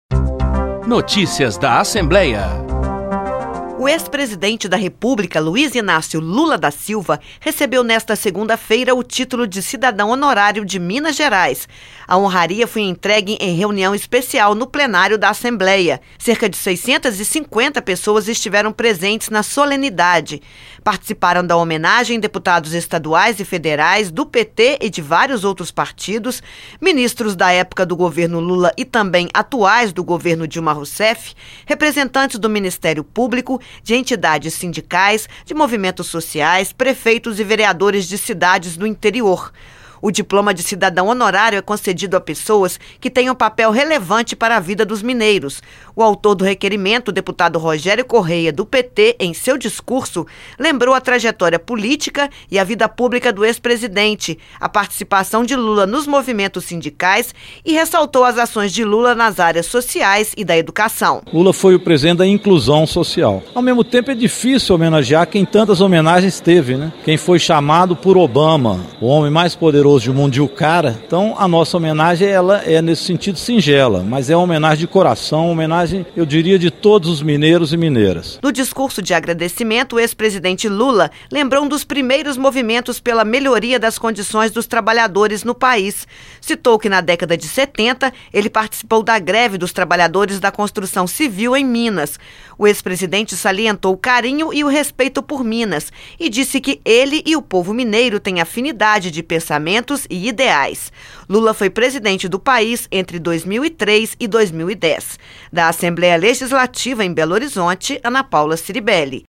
A homenagem, feita durante reunião especial, reuniu centenas de pessoas no Plenário da ALMG.